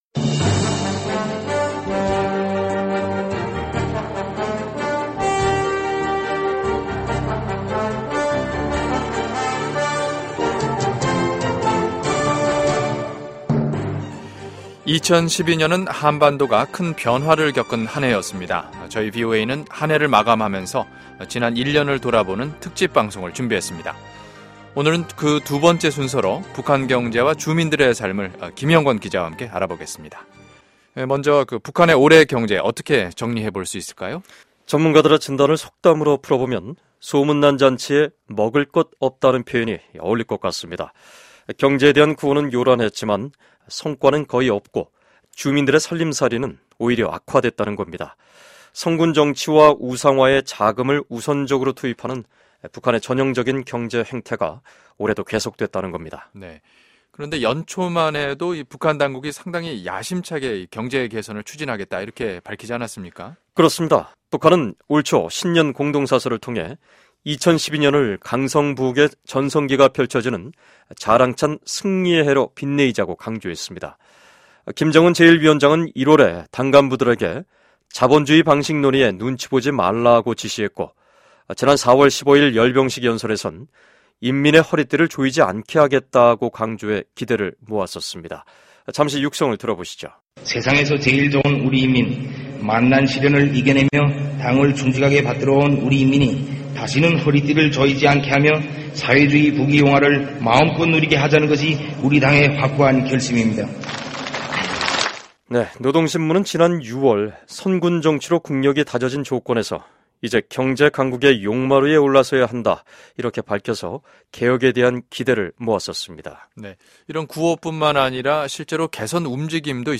2012년은 한반도가 국내외적으로 큰 변화를 겪은 한 해였습니다. 저희 VOA는 한 해를 마감하면서 북한 김정은 정권 1년을 돌이켜 보는 특집방송을 준비했습니다.